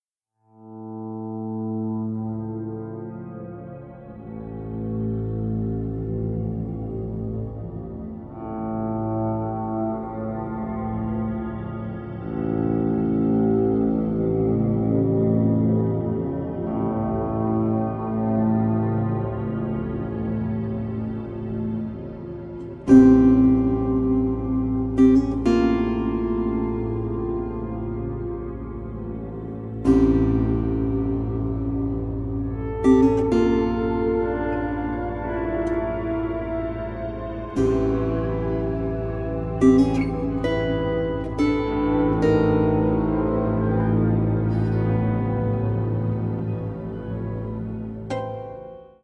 Electric guitar, Electric Baritone guitar